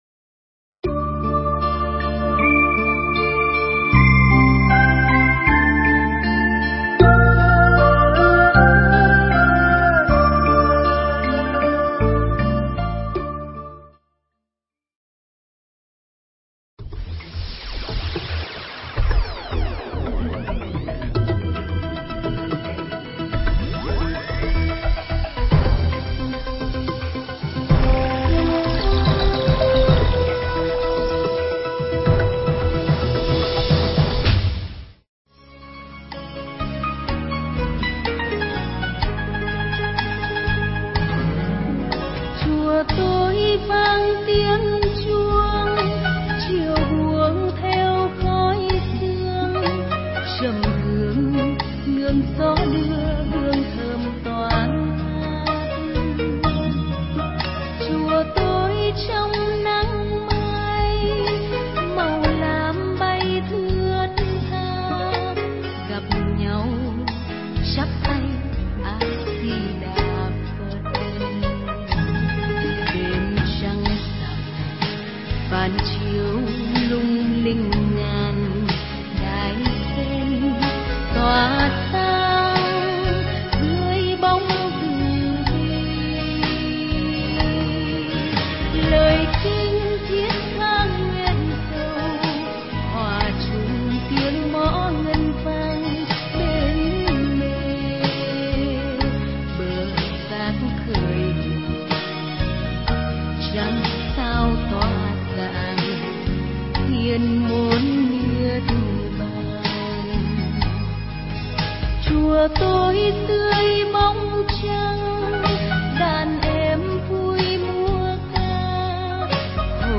Mp3 thuyết pháp